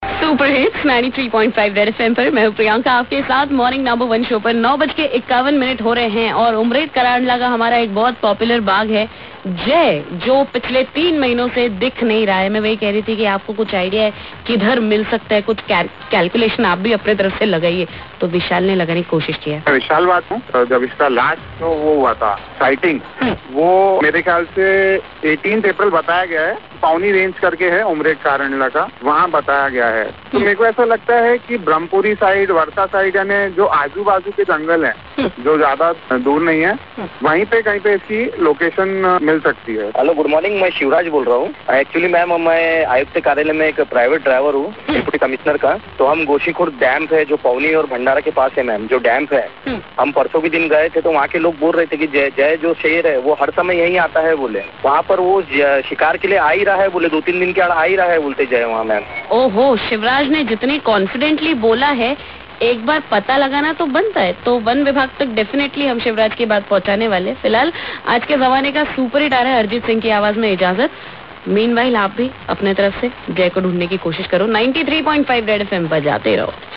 22JULY_L12_caller telling about jai